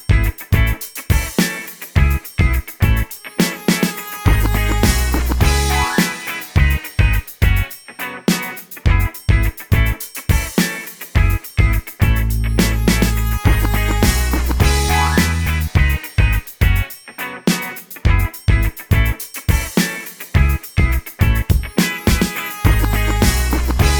Instrumental R'n'B